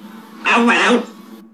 Arielle said,
Arielle can be a demanding bird.
Arielle understands speech and speaks
thoughtfully using English words,